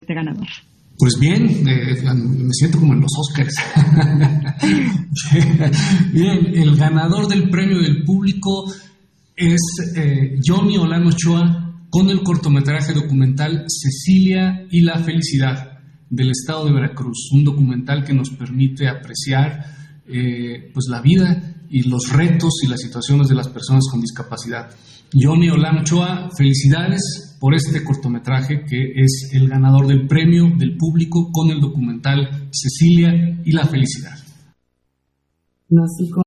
010721_AUDIO-2_INTERVENCIÓN-CONSEJERO-MARTÍN-FAZ-CONCURSO-VOTO-X-CORTO - Central Electoral